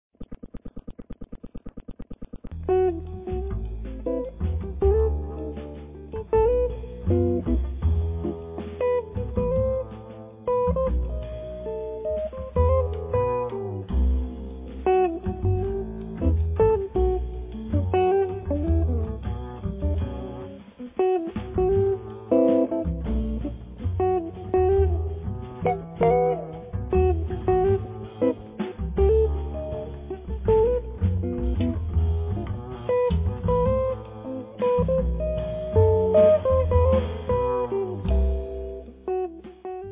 chitarra
contrabbasso
batteria
Un disco fatto di atmosfere evocative